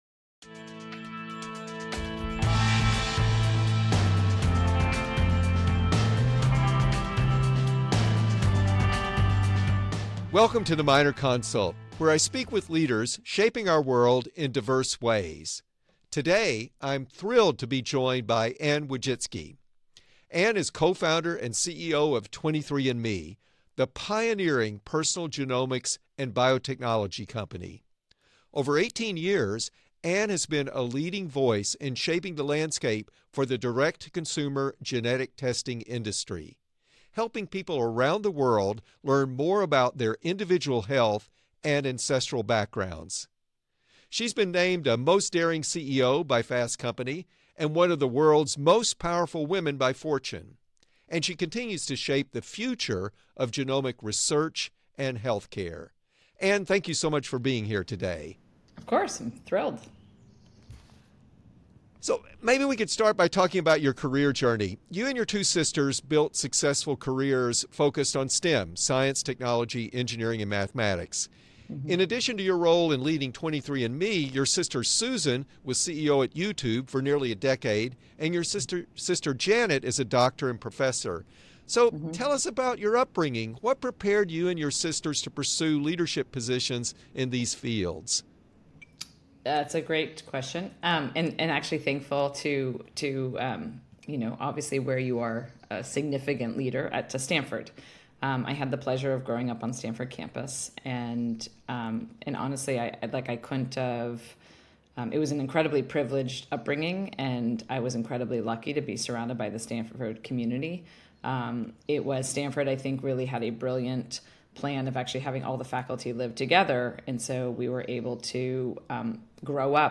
Dean Lloyd Minor welcomes Anne Wojcicki, CEO and co-founder of 23andMe, for a conversation about how personal genetics can empower consumers to drive healthier futures.